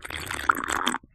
tbd-station-14/Resources/Audio/Items/drink.ogg
drink.ogg